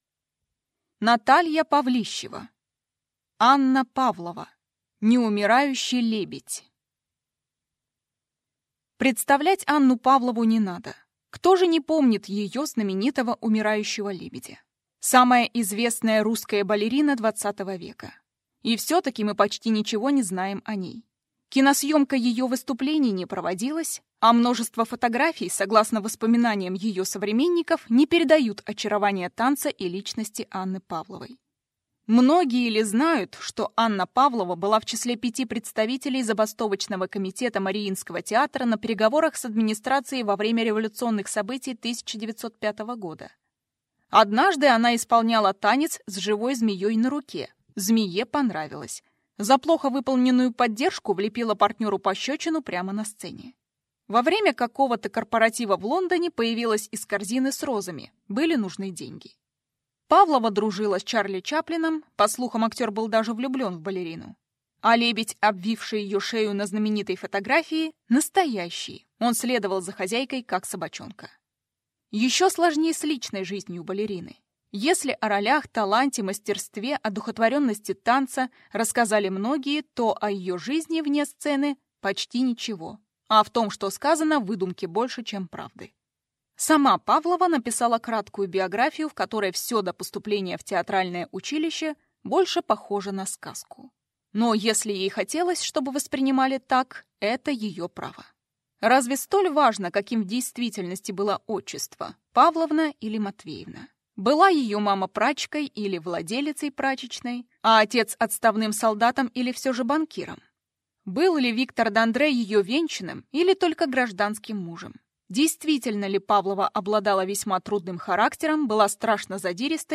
Аудиокнига Анна Павлова. «Неумирающий лебедь» | Библиотека аудиокниг